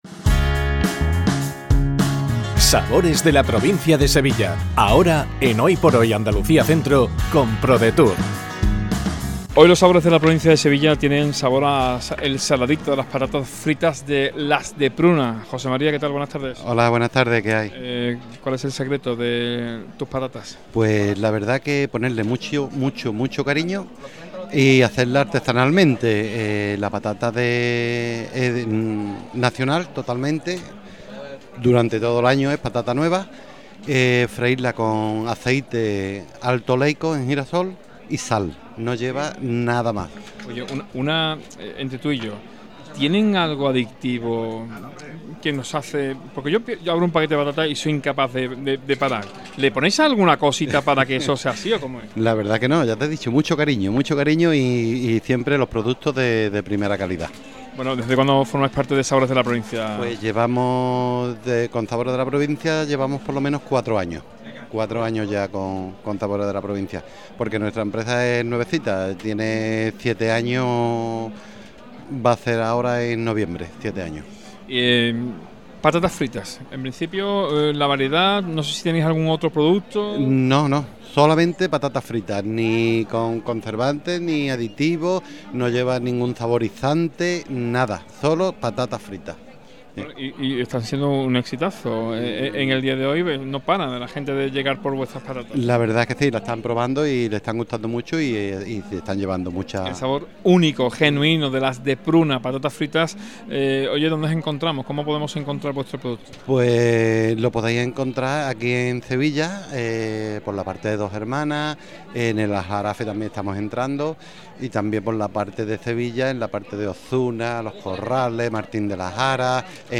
ENTREVISTA | Patatas fritas Las de Pruna